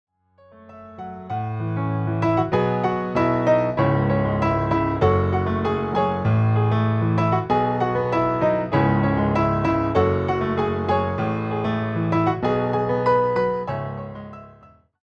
reimagined as solo piano arrangements.